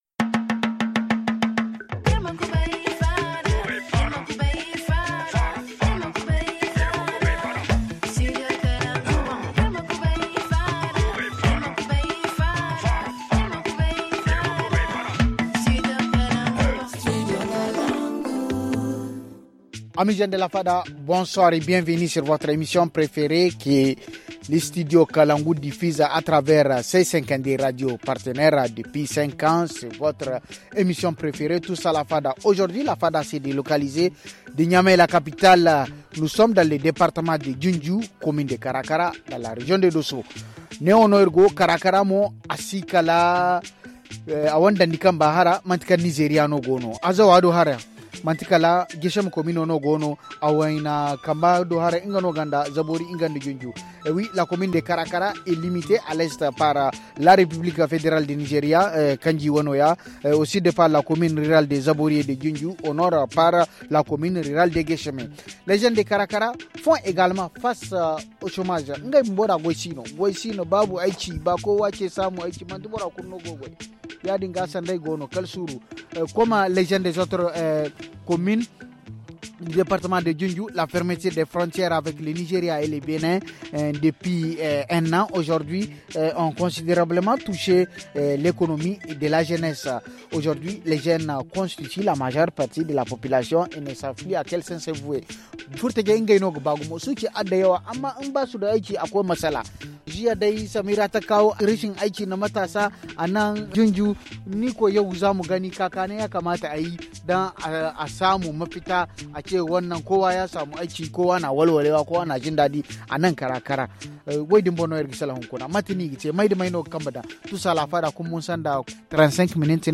Aujourd’hui la fada s’est délocalisé de Niamey la capitale au département de Dioundjou commune de Kara kara dans la région de Dosso.